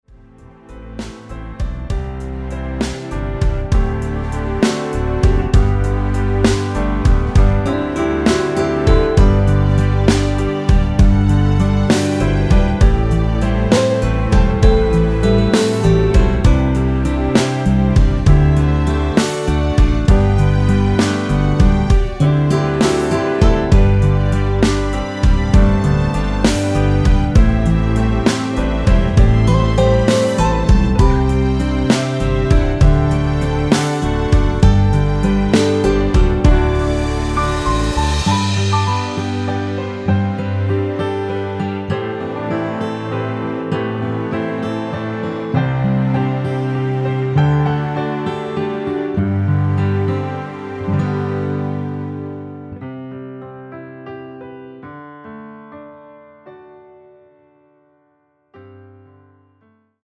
고음 부분의 부담없이 부르실수 있게 편곡 하였습니다.
앞부분30초, 뒷부분30초씩 편집해서 올려 드리고 있습니다.
중간에 음이 끈어지고 다시 나오는 이유는